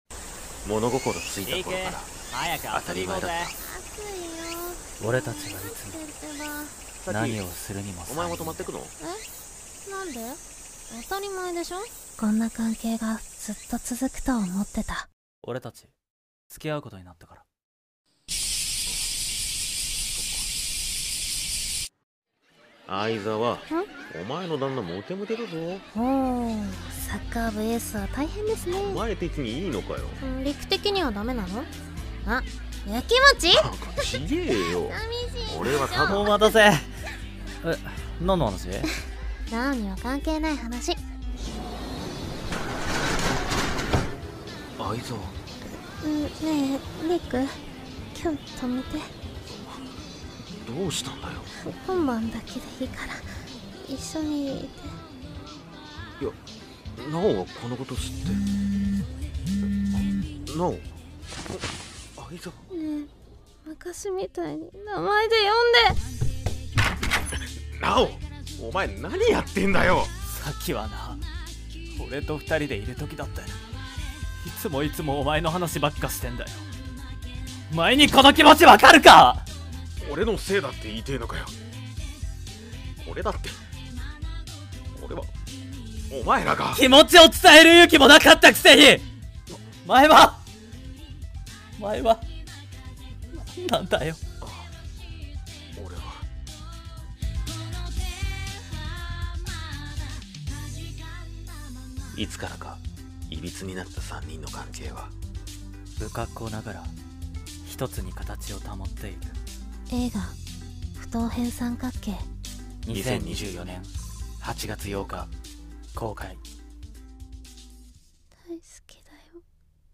【声劇】不等辺三角形